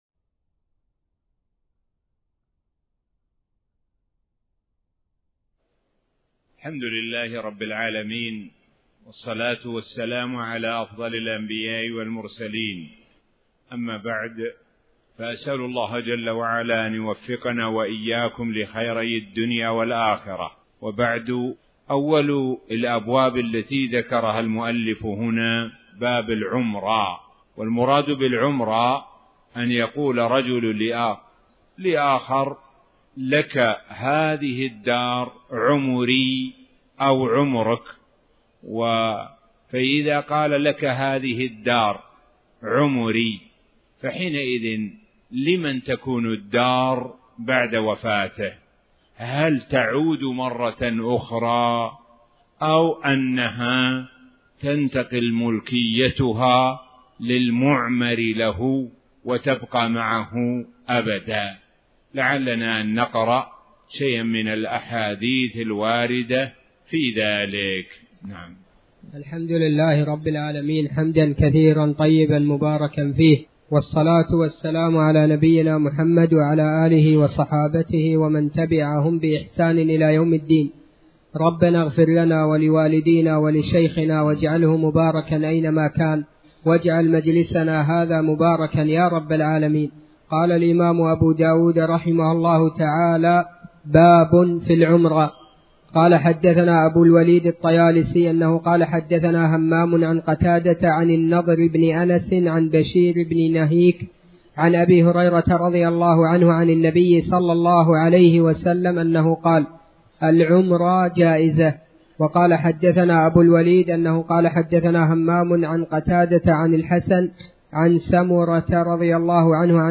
تاريخ النشر ٢٨ ذو الحجة ١٤٣٩ هـ المكان: المسجد الحرام الشيخ: معالي الشيخ د. سعد بن ناصر الشثري معالي الشيخ د. سعد بن ناصر الشثري أخر كتاب الإجارة